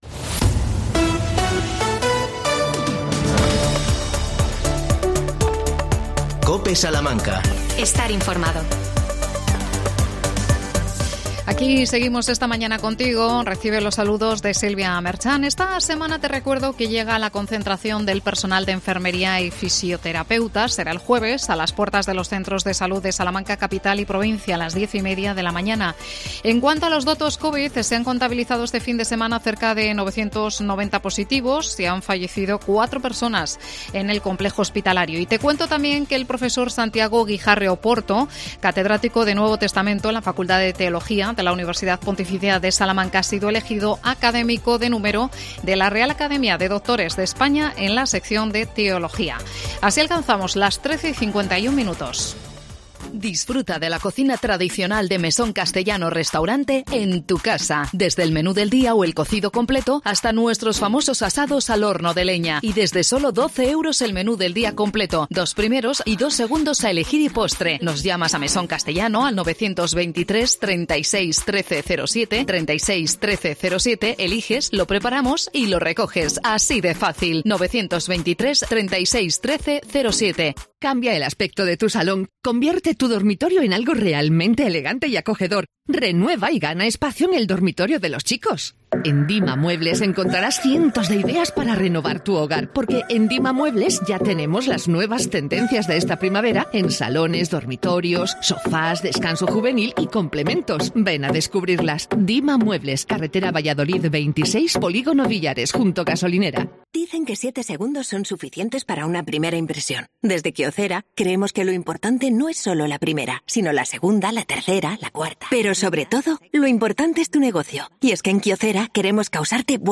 AUDIO: Deficiencias en materia de seguridad en el Servicio de Anatomía Patológica en el nuevo hospital. Entrevistamos